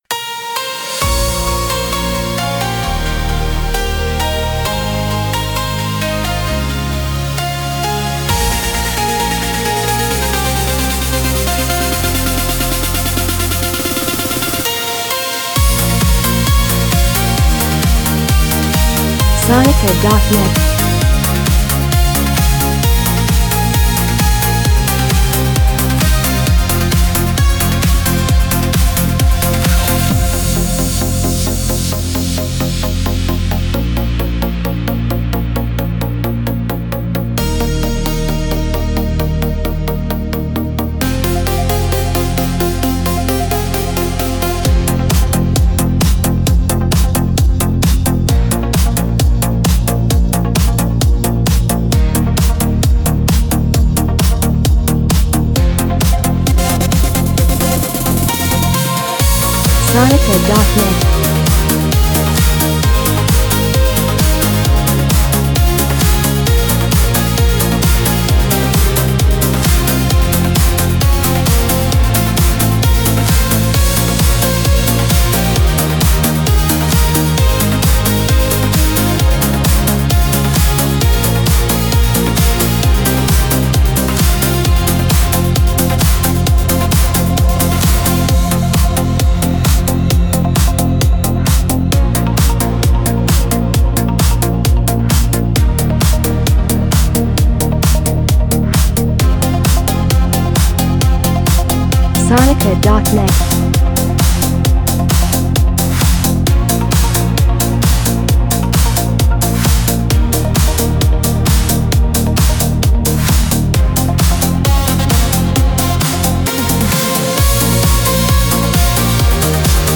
Eurodance Instr.